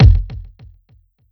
garden shed kick.wav